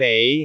speech
syllable
pronunciation